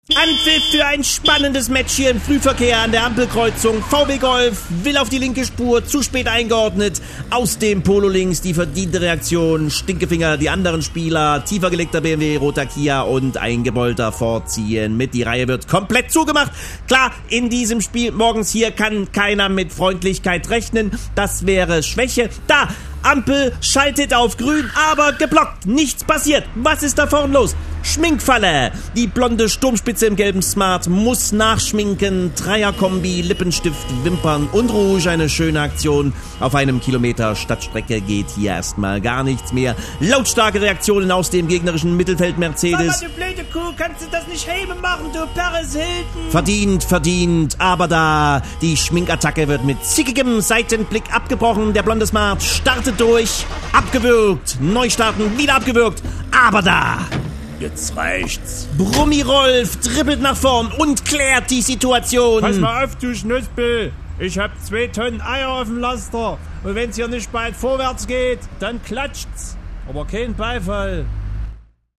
Er kann nicht anders: Er muss alles was morgens in Deiner Familie passiert kommentrieren: Als Fußballspiel!